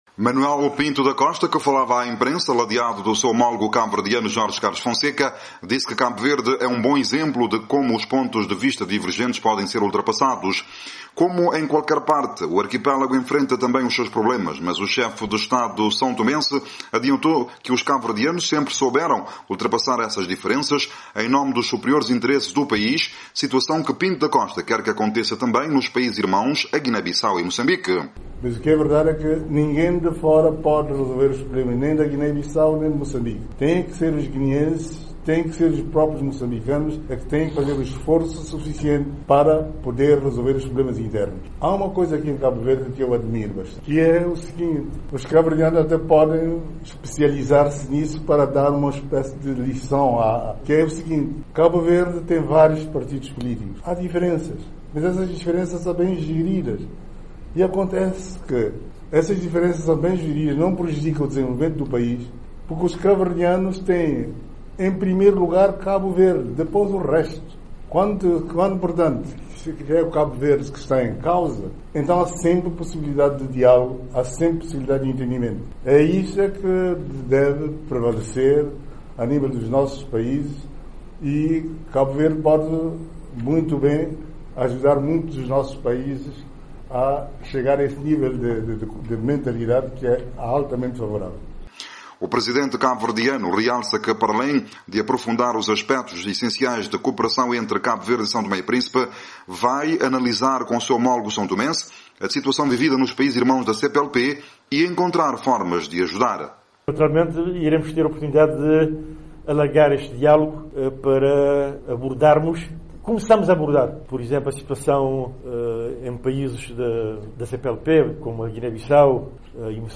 Manuel Pinto da Costa, que falava à imprensa junto do seu homologo cabo-verdiano Jorge Carlos Fonseca, disse que Cabo Verde é um bom exemplo de como os pontos de vista divergentes podem ser ultrapassados.